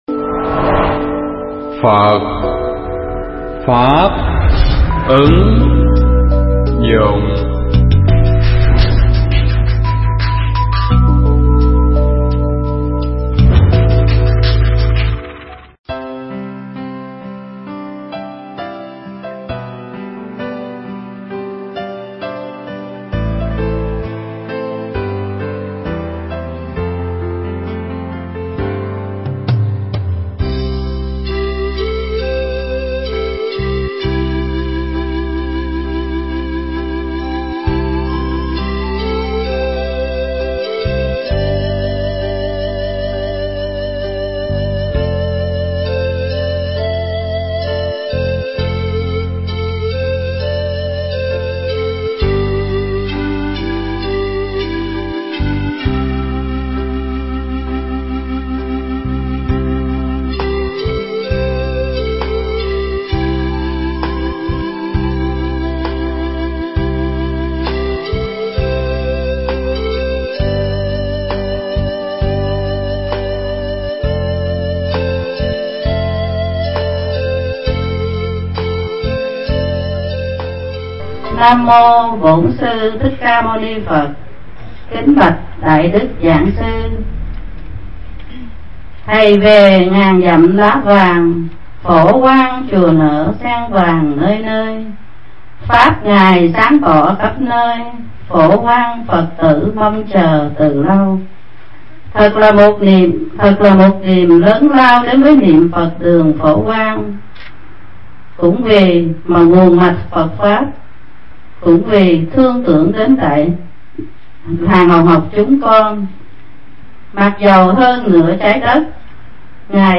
Bài giảng Bài Bạc Là Bác Thằng Bần
tại Niệm Phật Đường Phổ Quang (Hoa Kỳ)